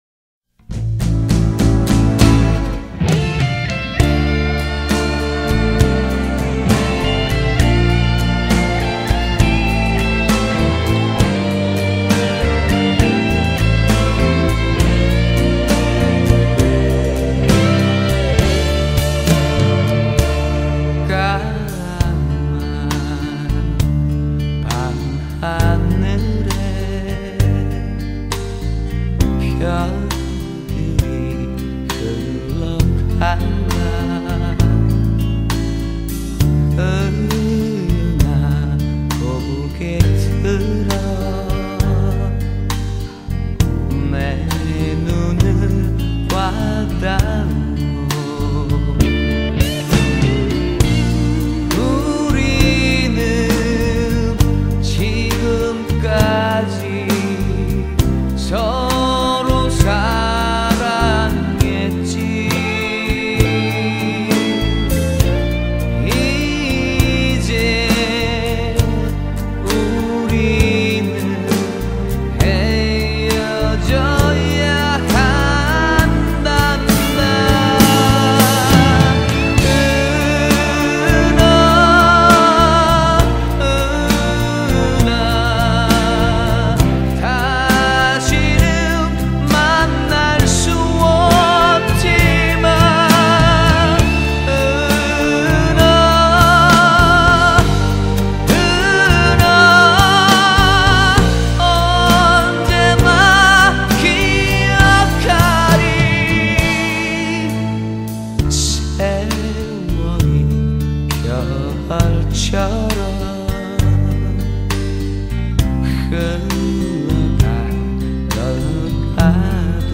♪♪ 음악 배달부 2 ♪♪/포크송 모음집